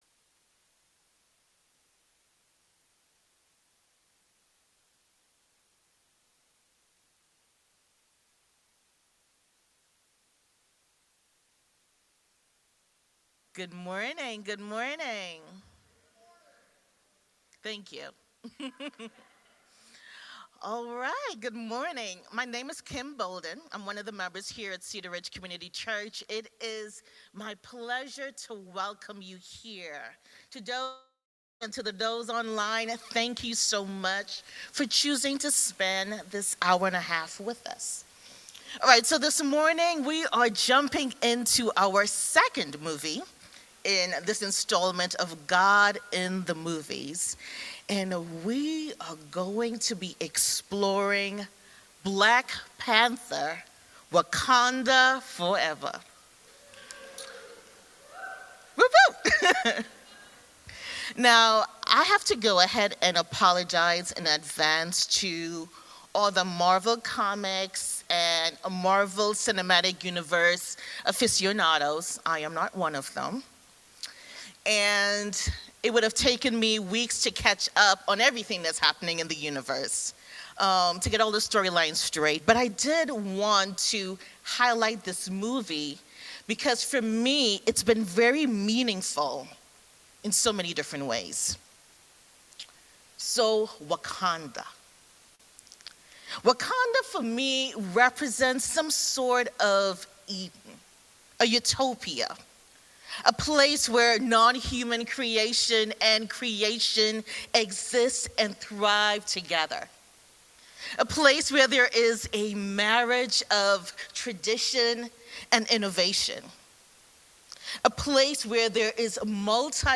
A message from the series "God in the movies 2024."
Through our traditional summer series, “God in the Movies,” we hear from community members about how a movie has stirred them to a deeper connection to Jesus.